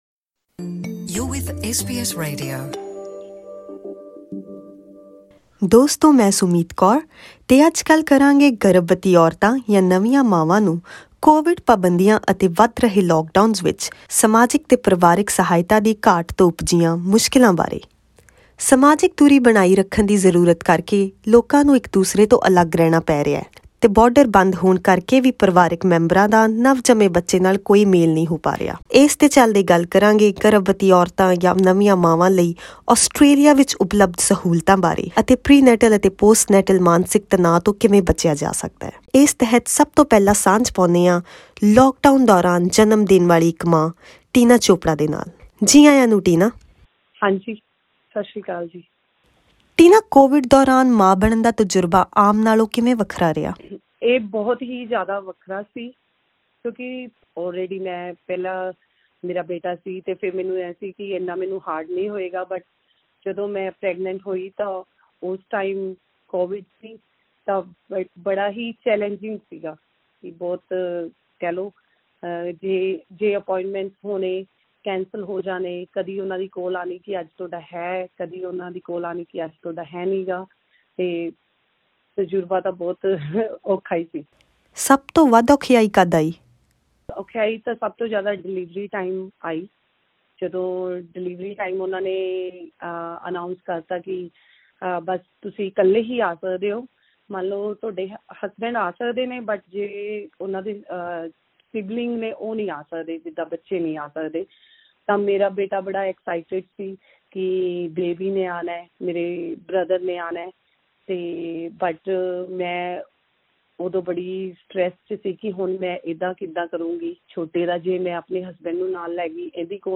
ਤਾਲਾਬੰਦੀ ਦੌਰਾਨ ਨਿਯਮਤ ਸਿਹਤ ਸਹੂਲਤਾਂ ਦੇ ਪ੍ਰਭਾਵਿਤ ਹੋਣ ਕਾਰਨ, ਗਰਭ ਅਵਸਥਾ ਅਤੇ ਇੱਕ ਨਵੀਂ ਮਾਂ ਬਣਨਾ ਤਣਾਅਪੂਰਨ ਹੋ ਰਿਹਾ ਹੈ। ਬਾਰਡਰ ਬੰਦ ਹੋਣ ਕਾਰਨ, ਕੋਵਿਡ ਵਿੱਚ ਮਾਂ ਬਣਨ ਦਾ ਤਜੁਰਬਾ ਆਮ ਨਾਲੋਂ ਕਿੰਨਾ ਕੁ ਵੱਖਰਾ ਹੈ ਅਤੇ ਆਸਟ੍ਰੇਲੀਆ ਵਿੱਚ ਇਸ ਤਹਿਤ ਕਿਹੜੀਆਂ ਸਹੂਲਤਾਂ ਉਪਲਭਦ ਹਨ ਇਸ ਬਾਰੇ ਜਾਨਣ ਲਈ ਸੁਣੋ ਇਹ ਖਾਸ ਗੱਲਬਾਤ।